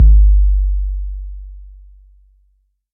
美味的808脚
标签： 140 bpm Trap Loops Drum Loops 509.07 KB wav Key : Unknown
声道立体声